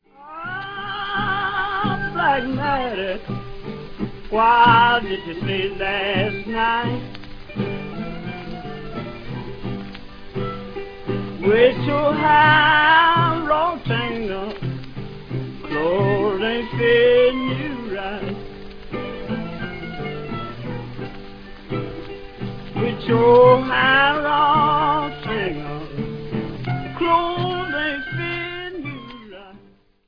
вокал, гитара
мандолина
гармоника